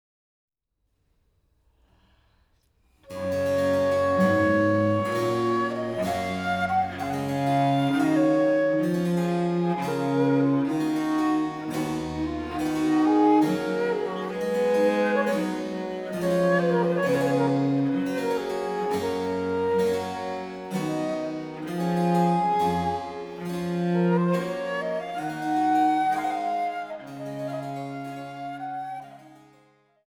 Traversflöte
Viola da gamba, Barockcello
Lentement